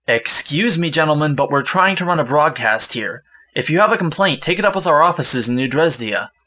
Human Male, Age 24